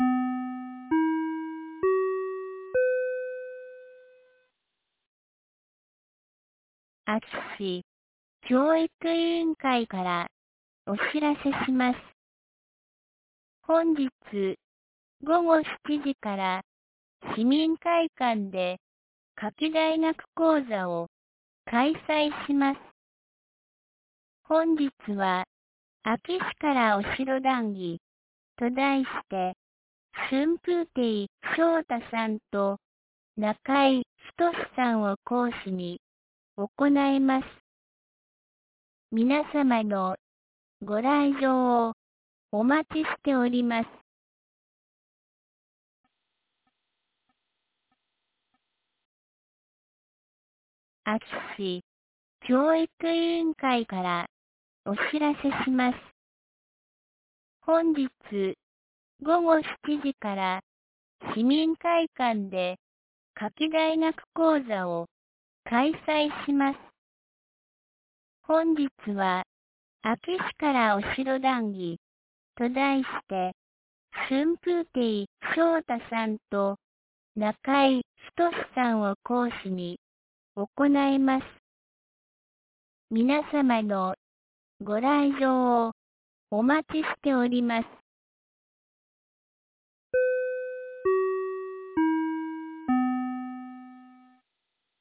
2024年08月21日 17時11分に、安芸市より全地区へ放送がありました。